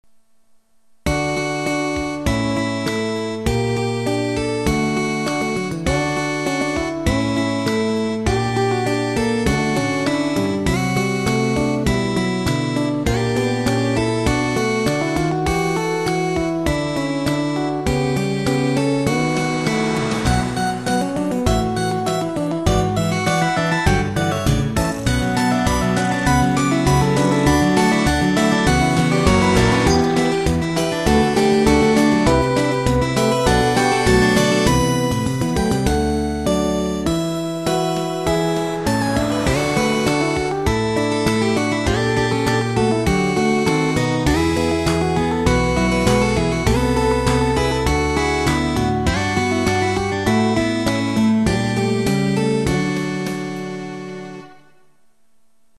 短いギターっぽい曲。
最初期の曲のひとつで音割れあり。